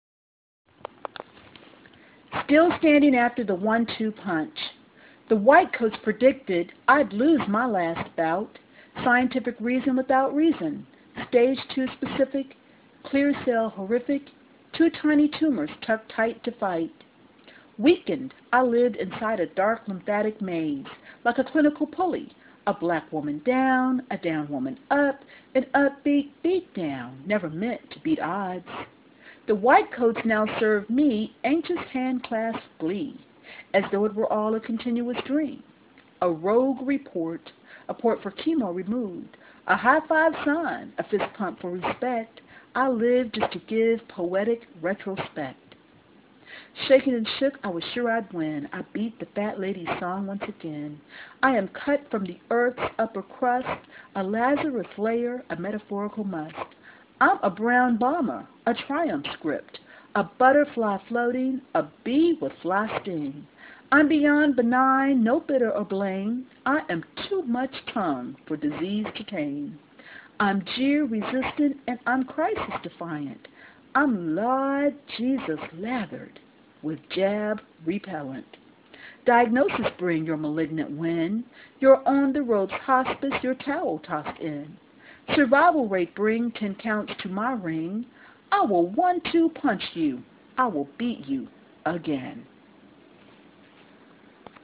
Still Standing After The One-Two Punch (Live Recording)
35th Annual Cultural Council Awards Luncheon.